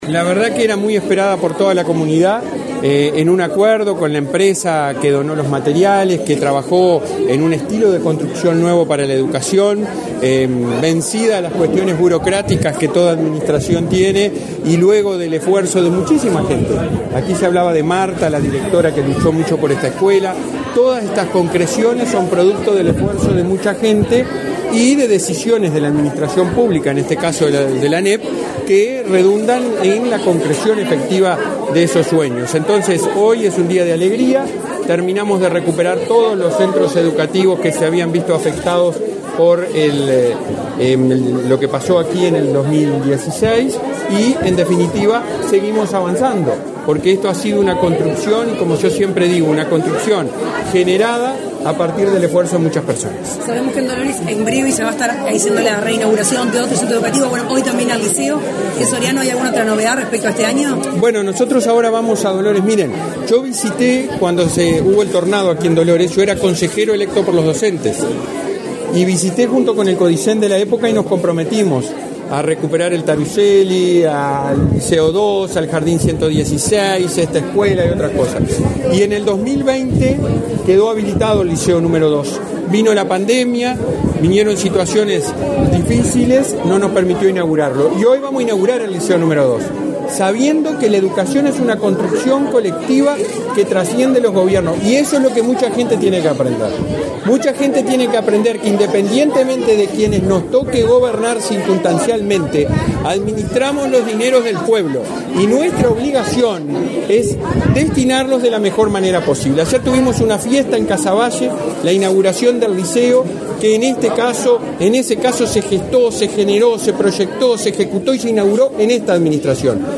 Declaraciones a la prensa del presidente de la ANEP, Robert Silva
Tras el evento, el jerarca realizó declaraciones a la prensa.